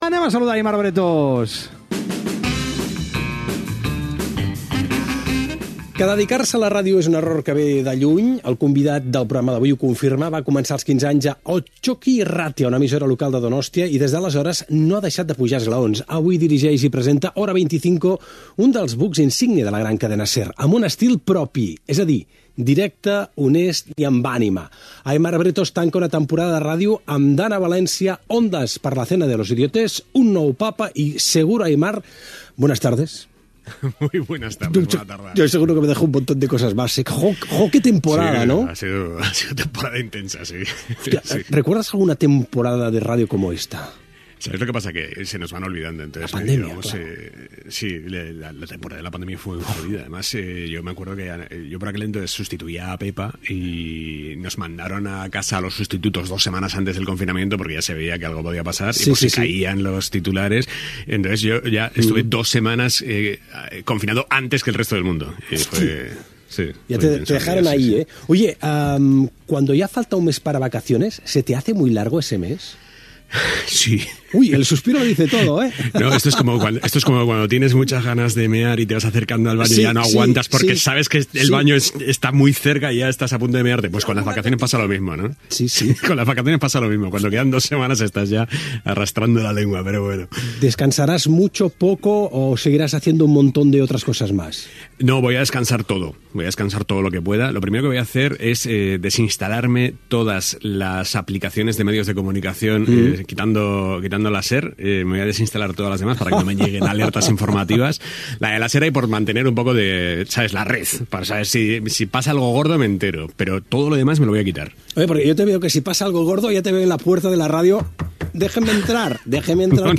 Fragment d'una entrevista al periodista Aimar Bretos, director del programa "Hora 25".
Entreteniment
FM